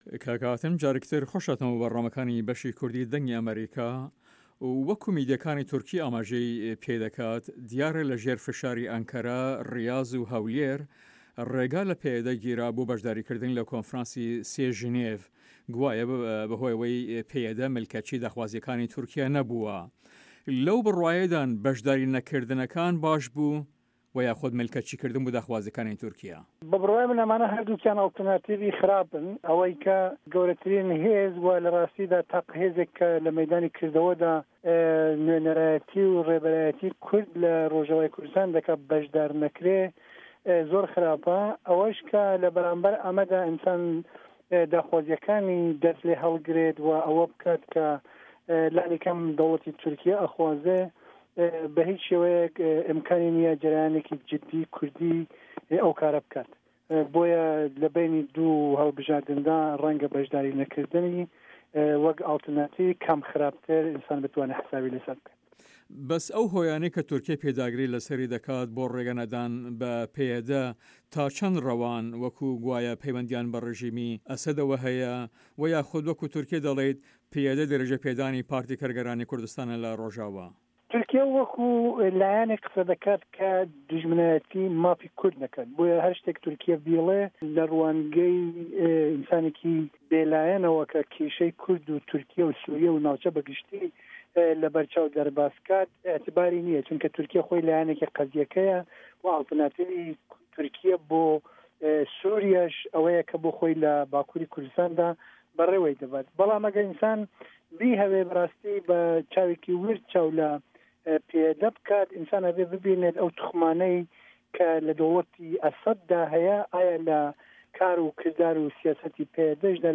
هەڤپەیڤینێکدا